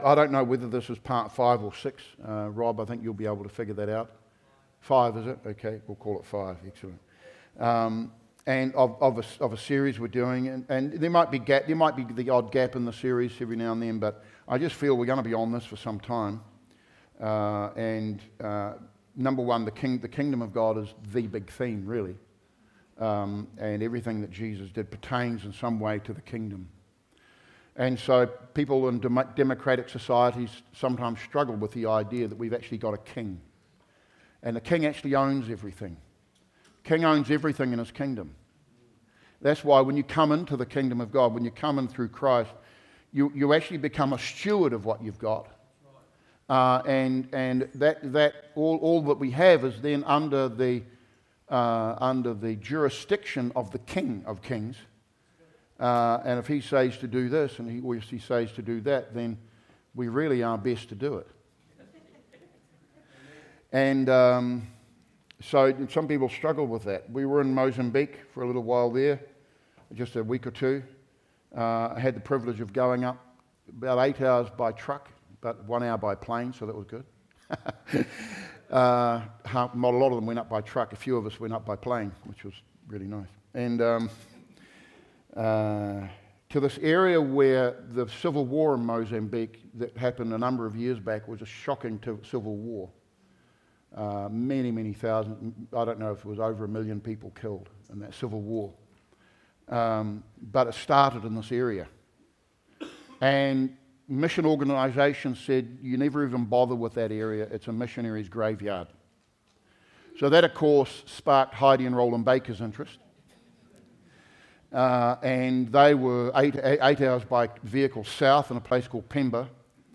Sermons | Living Waters Christian Centre